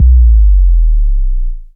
Pitch Sweep Bass.wav